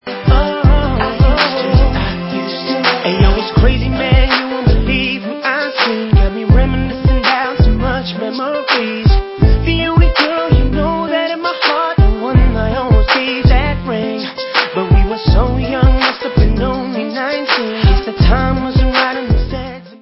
sledovat novinky v kategorii Dance